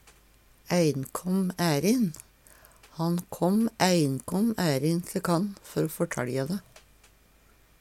einkåm ærin - Numedalsmål (en-US)